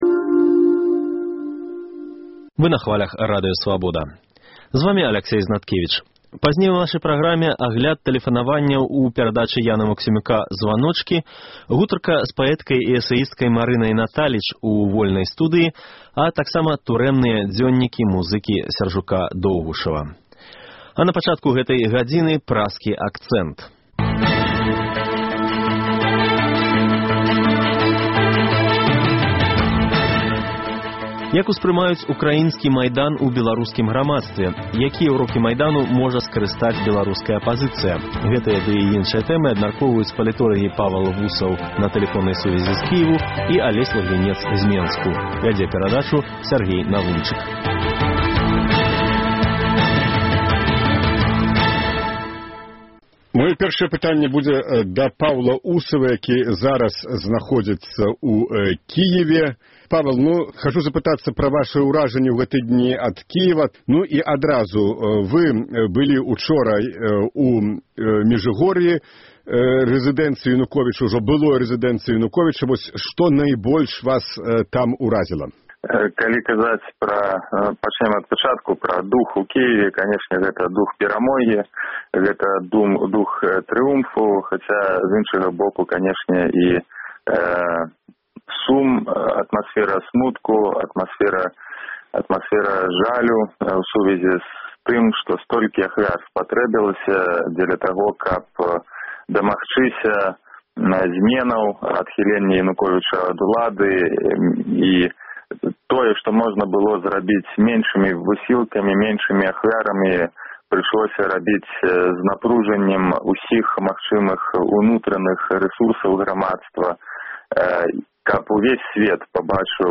на тэлефоннай сувязі ў Кіеве